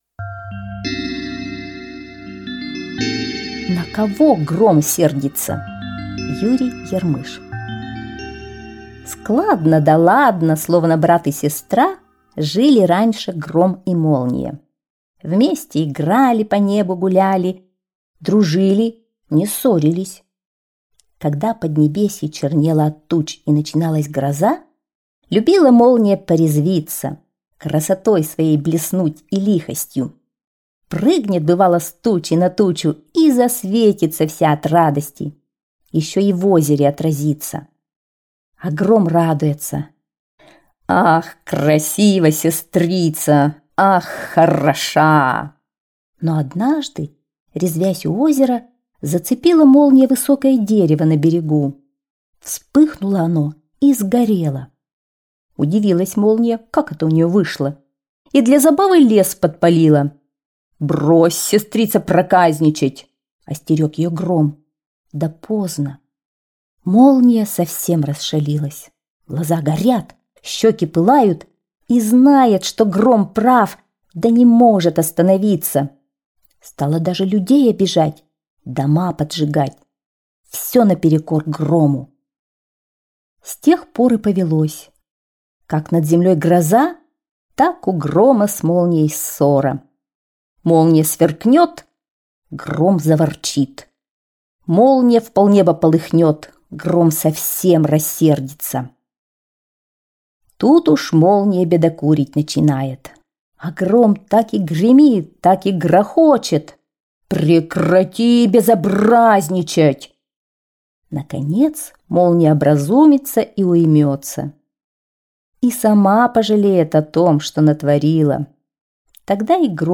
На кого Гром сердится - аудиосказка Ярмыша Ю.Ф. История о том, как Гром рассердился на Молнию за то, что она лес подожгла.